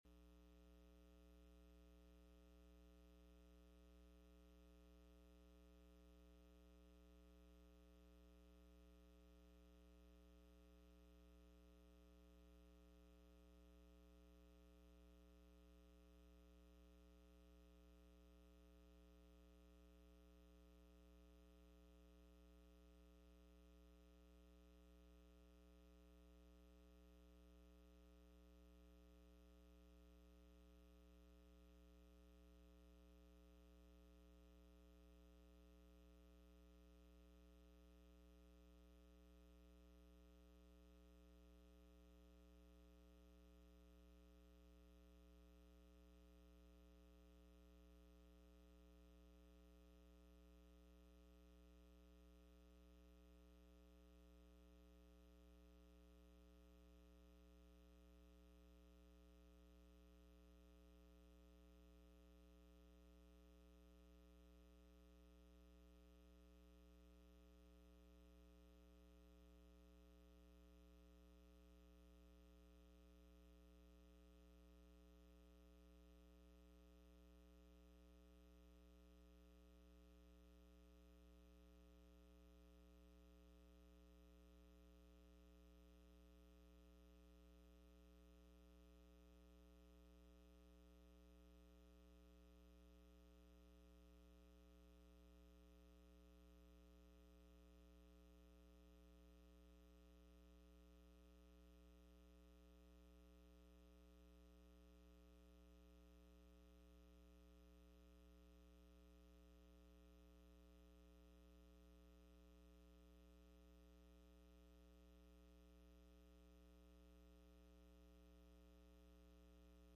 Matthew 6:1-21 Service Type: Midweek Meeting « When Jesus Sees Faith